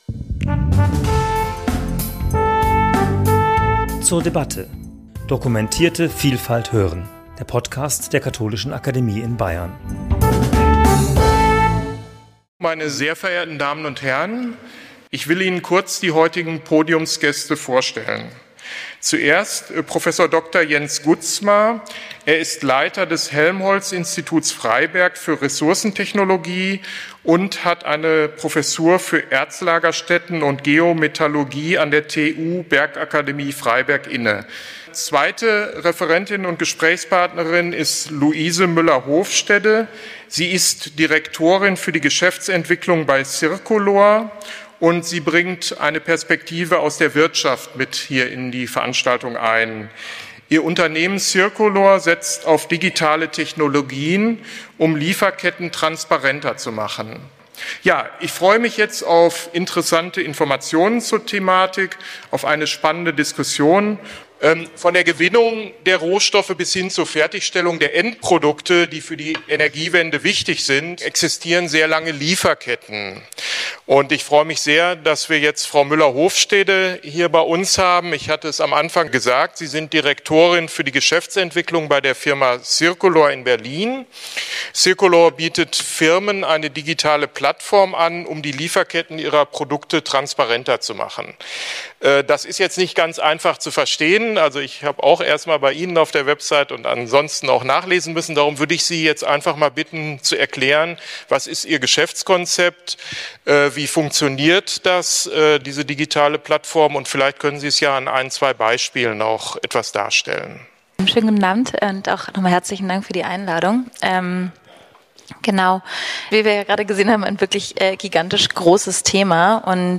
Gespräch zum Thema 'Rohstoffe für die Energiewende - Lieferketten, Abhängigkeiten und Verantwortung' ~ zur debatte Podcast
Gemeinsam mit Fachleuten aus Wissenschaft und Wirtschaft und auch mit Ihnen diskutieren wir diese Fragen am Beispiel der für die Energiewende erforderlichen Rohstoffe wie Kupfer und Lithium. Wir werfen einen Blick auf den globalen Rohstoffhandel und die Lieferketten.